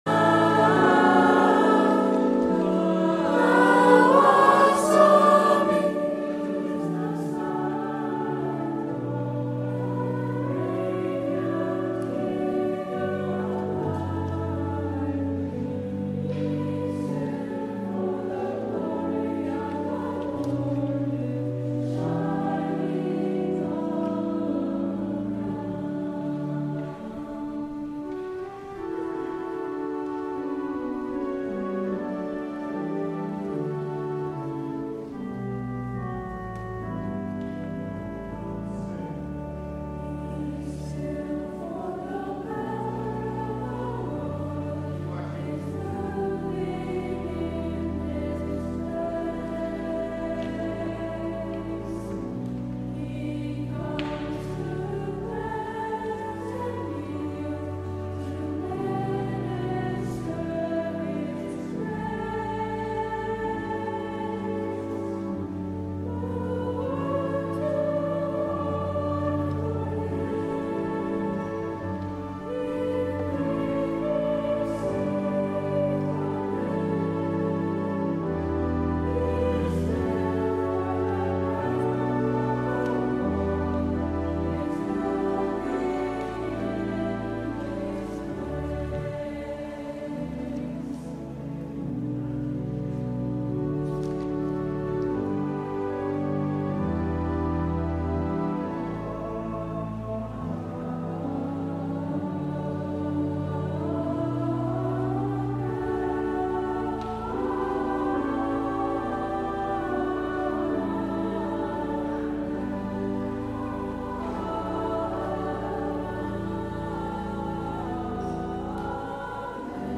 LIVE Morning Worship Service - The Prophets and the Kings: The Call of Elisha